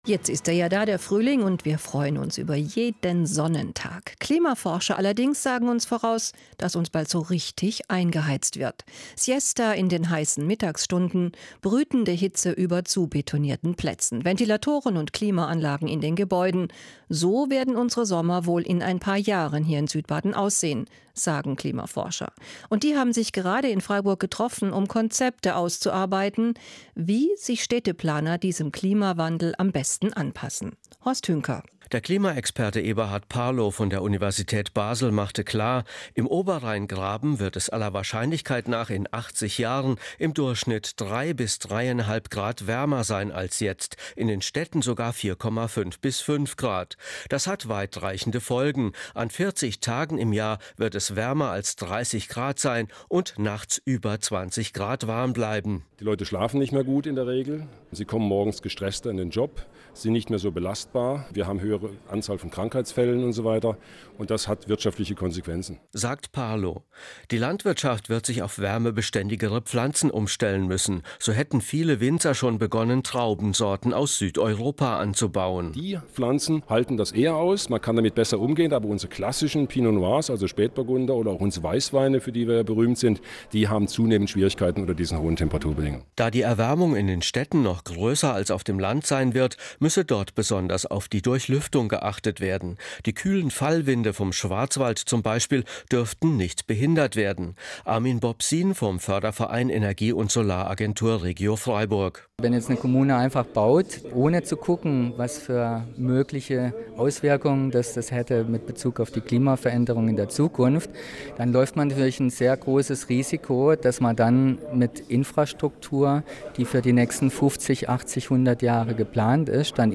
Das SWR4-Radio Berichtet: